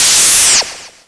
se_lazer01.wav